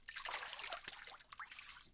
fishLine3.wav